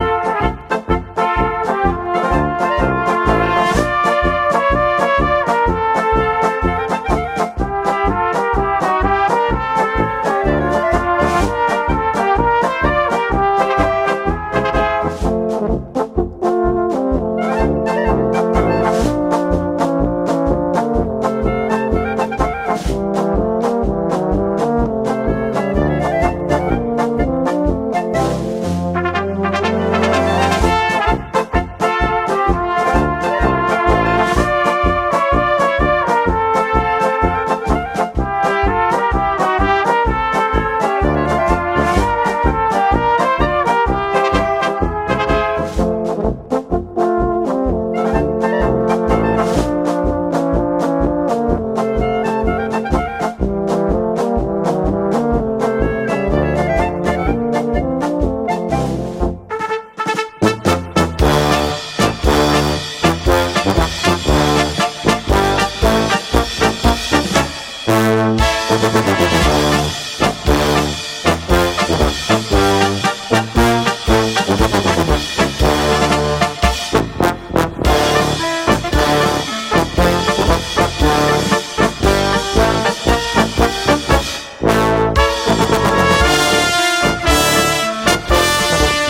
Gattung: Polka für Blasorchester
Besetzung: Blasorchester